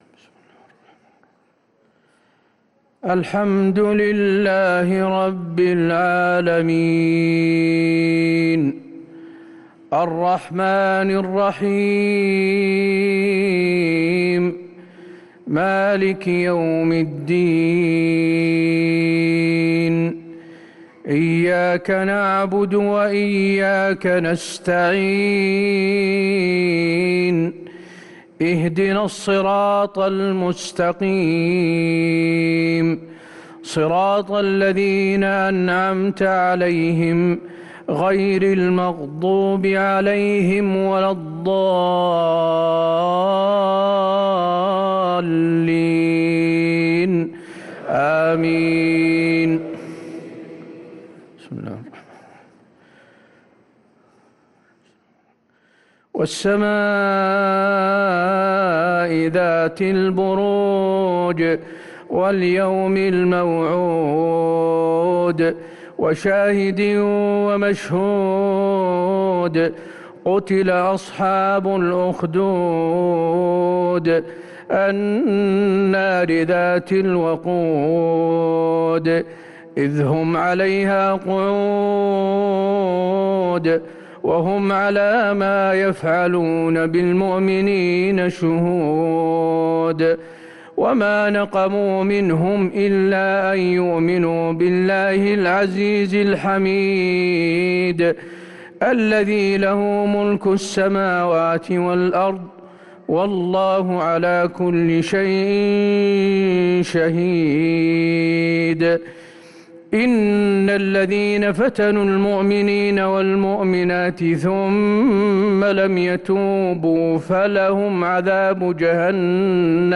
صلاة العشاء للقارئ حسين آل الشيخ 13 ذو الحجة 1444 هـ
تِلَاوَات الْحَرَمَيْن .